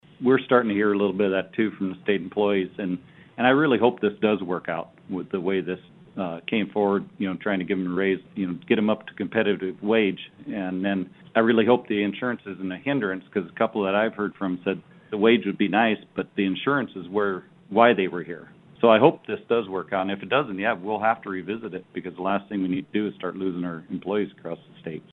South Dakota House leadership recap 2021 Legislative Session during a March 11, 2021, news conference.